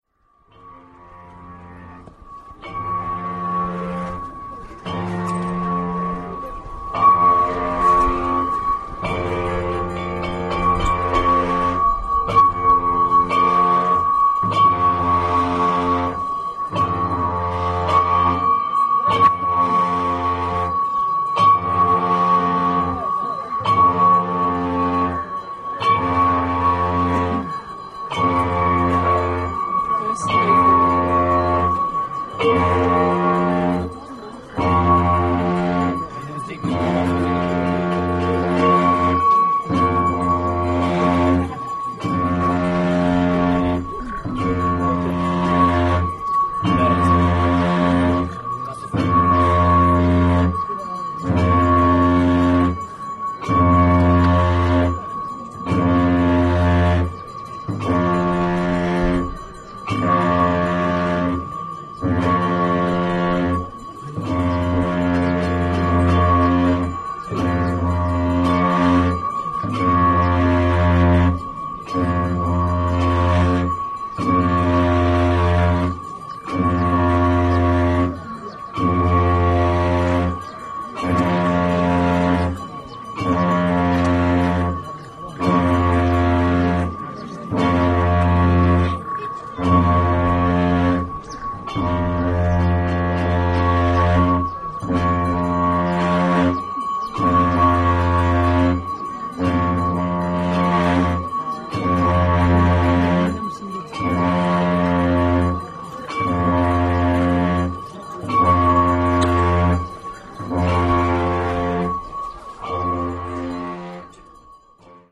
MP3 of "Puja Horns blessing" (MP3, 1.1 MB) MP3 of "Archery song" (MP3, 1.32 KB) MP3 of "Thimpu radio" (MP3, 800 KB)
hornsbless.mp3